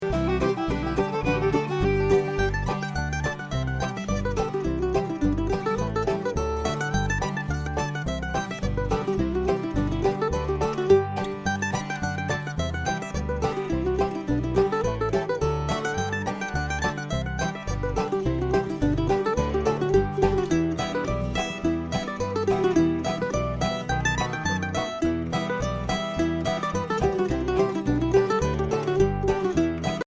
Voicing: Mandolin C